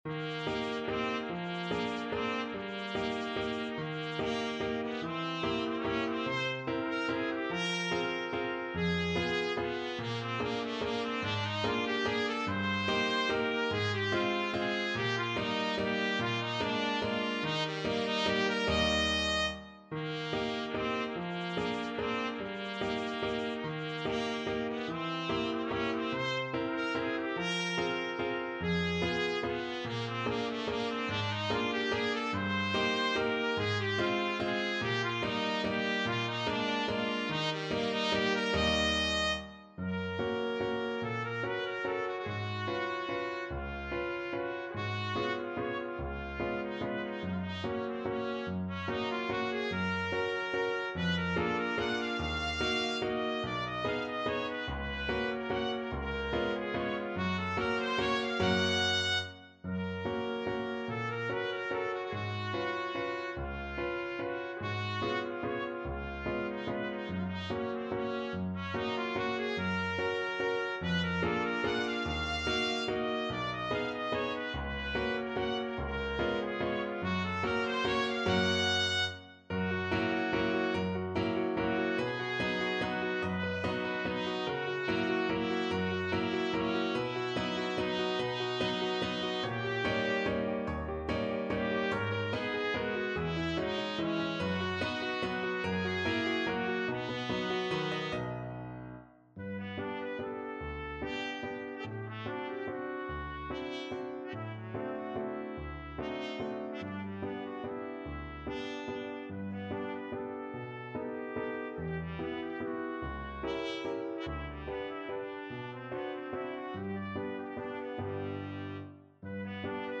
3/4 (View more 3/4 Music)
=145 Presto (View more music marked Presto)
F#4-F#6
Classical (View more Classical Trumpet Music)